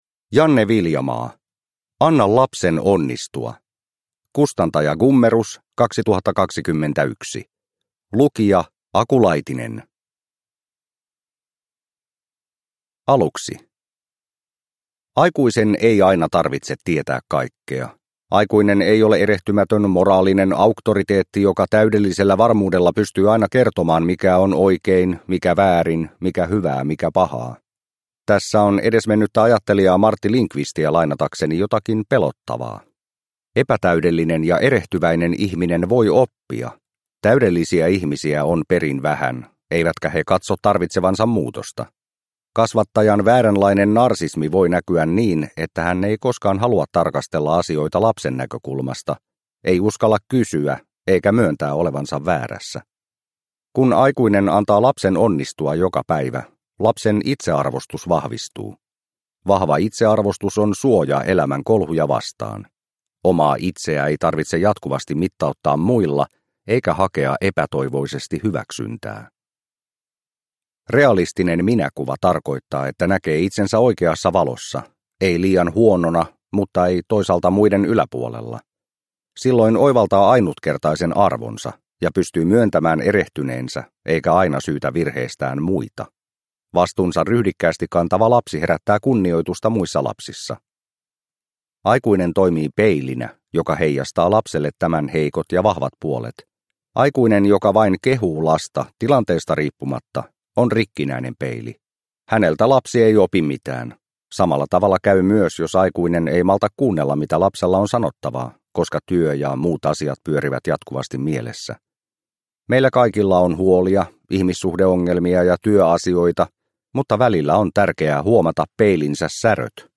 Anna lapsen onnistua – Ljudbok – Laddas ner